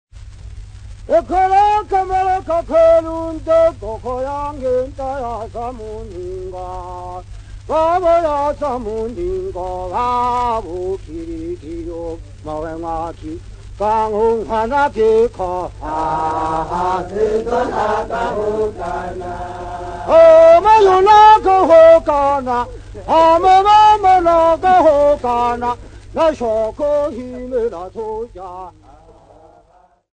Sukumu men
Folk Music
Field recordings
Africa Tanzania City not specified f-tz
Indigenous music